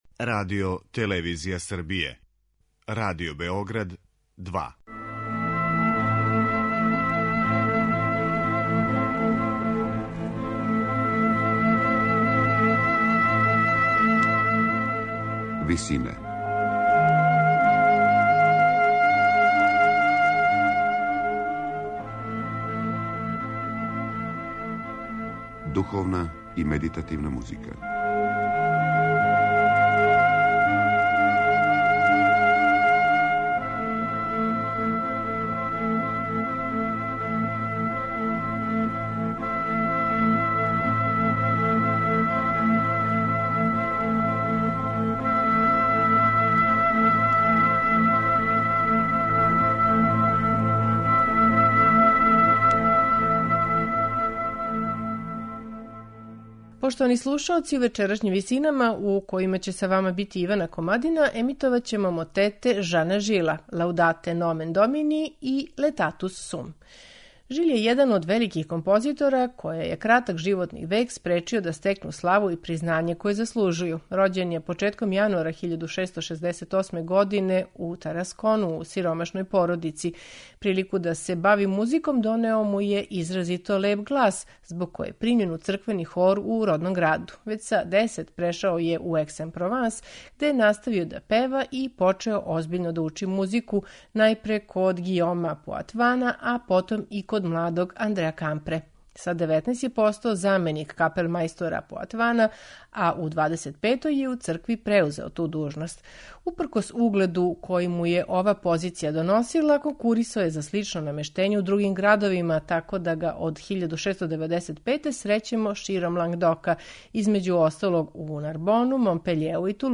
Eмитоваћемо мотет Laudate nomen Domini, француског барокног композитора Жана Жила.
овај Жилов мотет слушаћете у интерпретацији солиста, великог хора и инструменталног ансамбла Les Festes d'Orphée